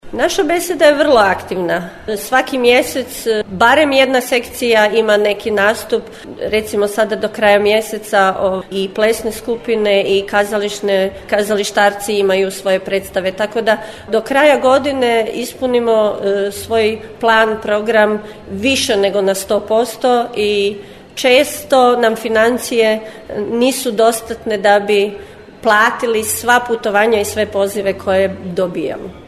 Održana izborna skupština Češke besede Daruvar